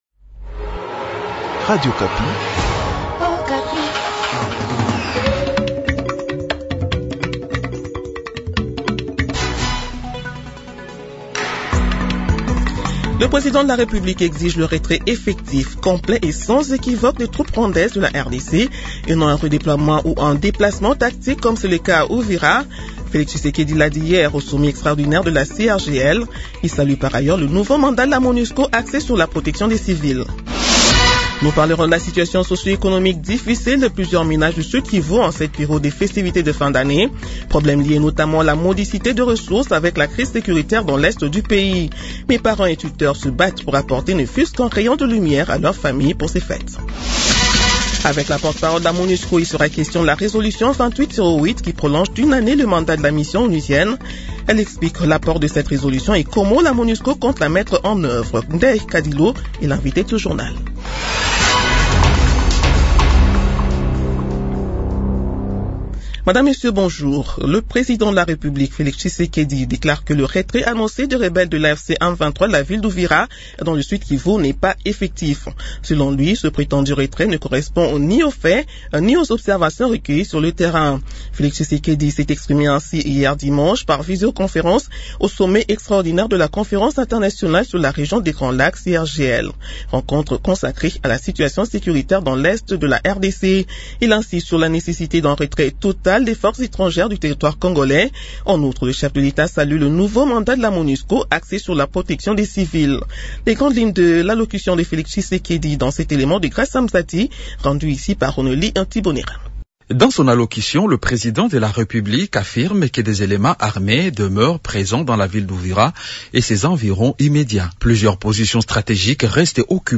JOURNAL MIDI DU LUNDI 22 DECEMBRE 2025